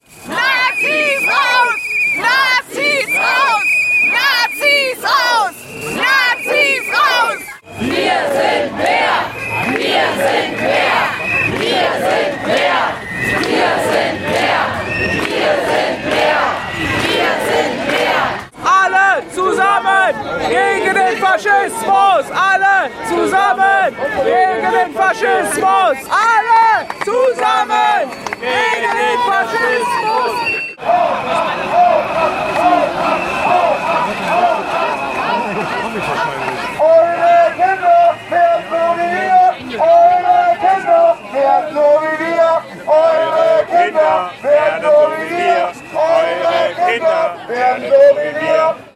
Kundgebung „Düsseldorf stellt sich quer gegen extreme Rechte und Rassismus!“ (Audio 5/7)
Protestrufe der Demonstrierenden (zusammengeschnitten) (Audio 5/7) [MP3]